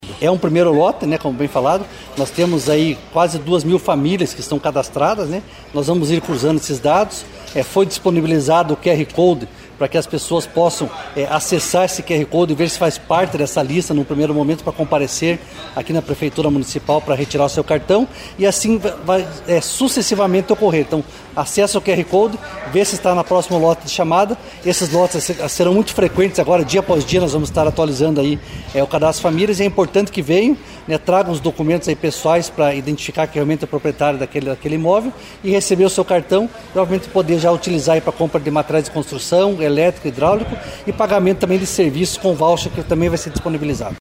O coordenador estadual da Defesa Civil, coronel Fernando Shunig, destacou que a entrega abrange um primeiro lote de famílias cadastradas.